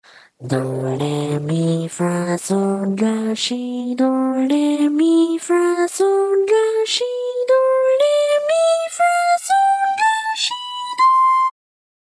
一音階癖弱音源
收錄音階：D#4